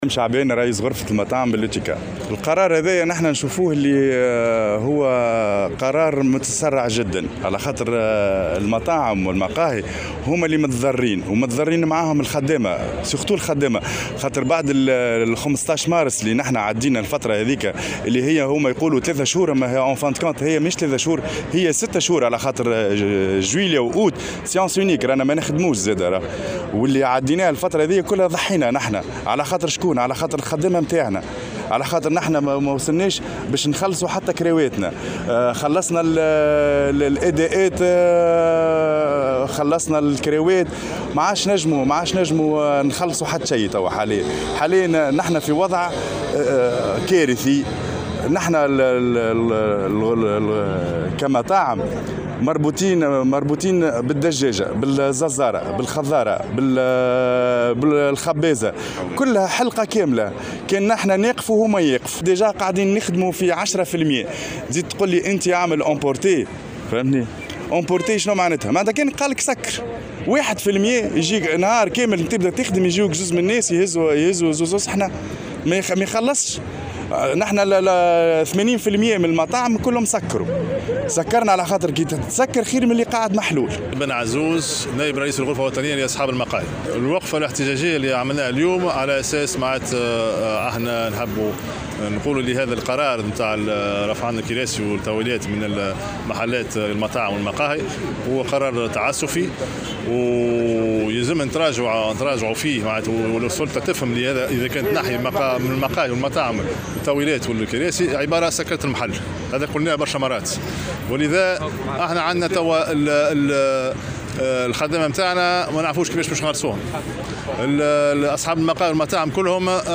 نفّذ صباح اليوم الجمعة، أصحاب المقاهي والمطاعم وقفة احتجاجية، أمام المسرح البلدي بتونس العاصمة، ضدّ قرار منع استعمال الكراسي والاقتصار على تقديم المأكولات والمشروبات المحمولة.